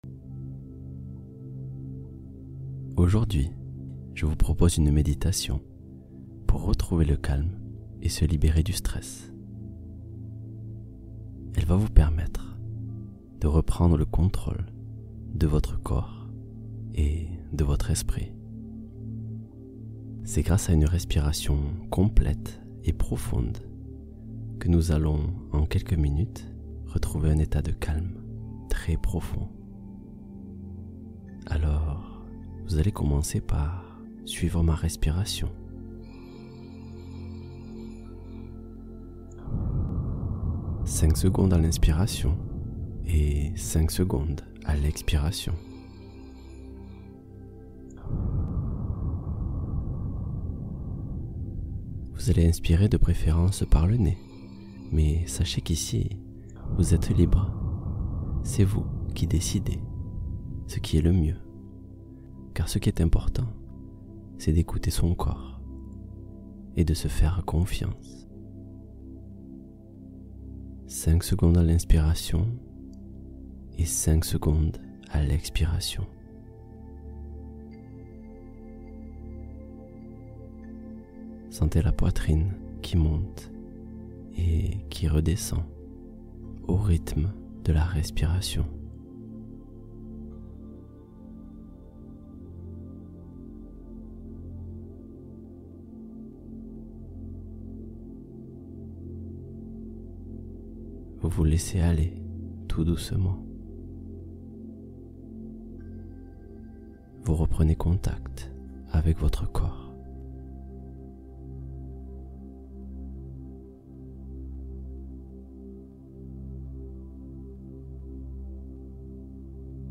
Dialogue avec l’inconscient — Exploration intérieure guidée